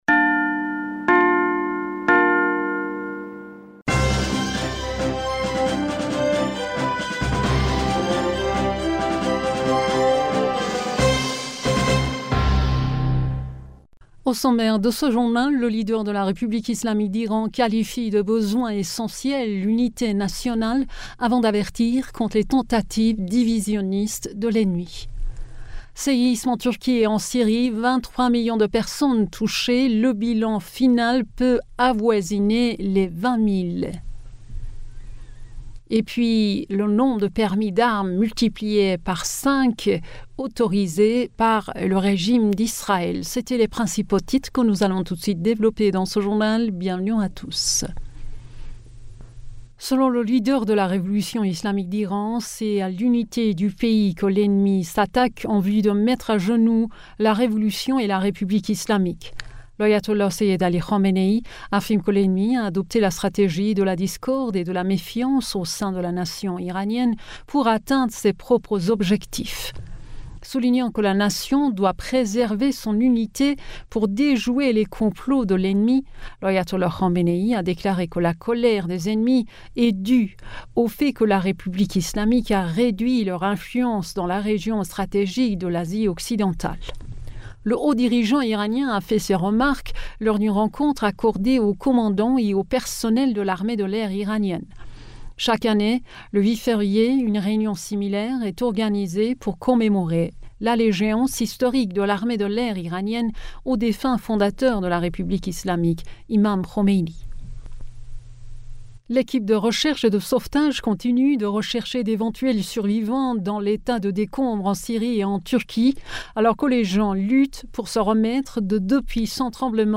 Bulletin d'information du 08 Février